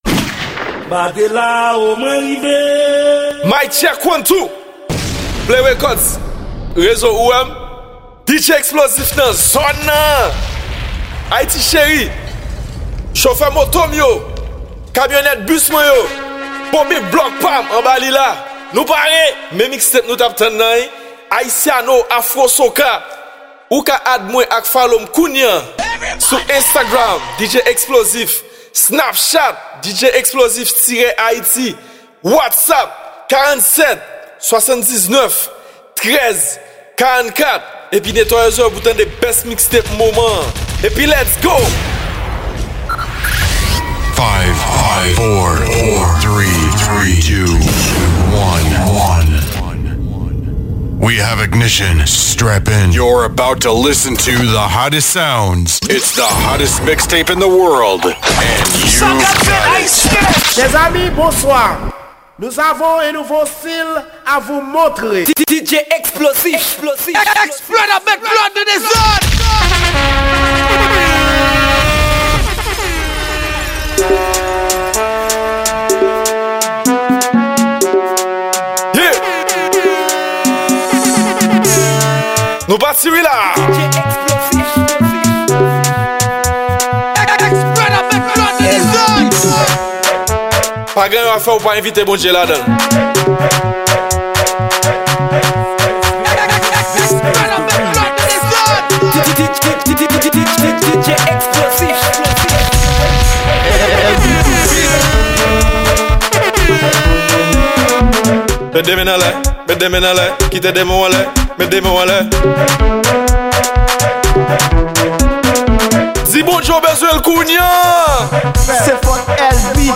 Genre: DJ Mixes.